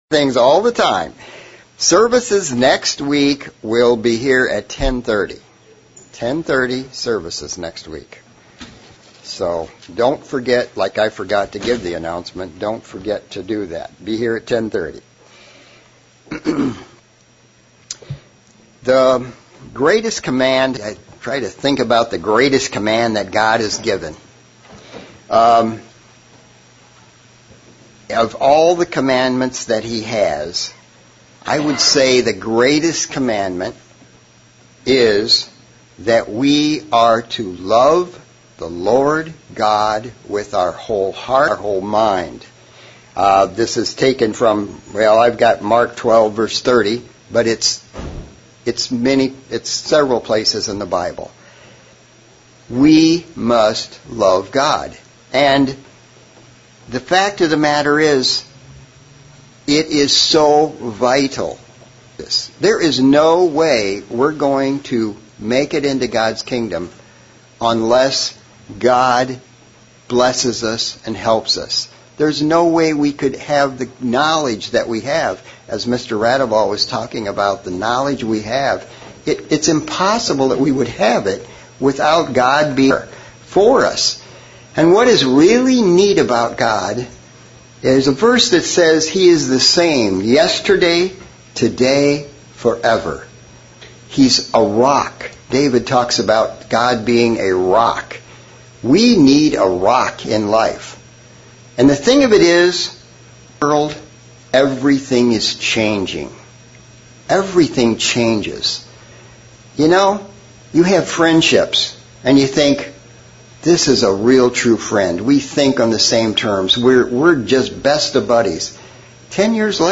Sermon looking at the end of the kingdom of Judah and their love of their idolatrous gods that pulled them away from the true God